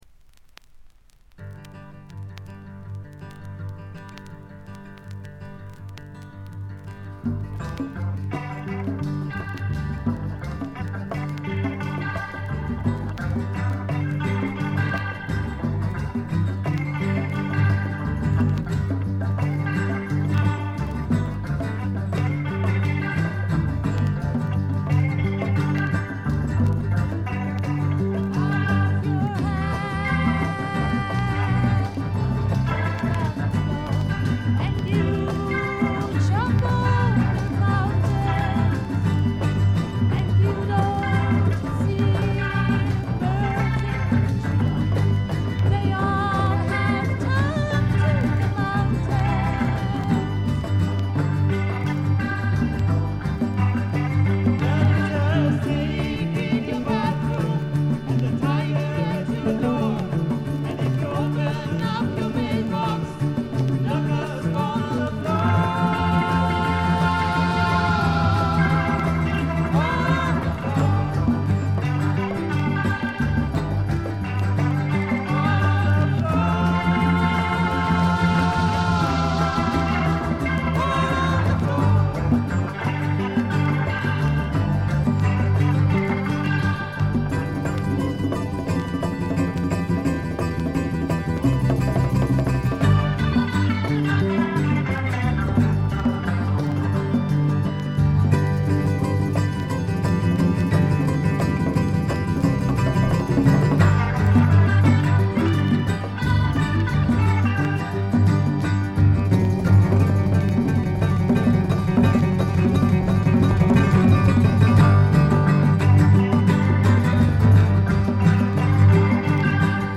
全体にバックグラウンドノイズが出ていますが静音部で分かる程度。散発的なプツ音が2-3回ほど。
ジャーマン・アンダーグラウンド・プログレッシヴ・サイケの雄が放った名作。
試聴曲は現品からの取り込み音源です。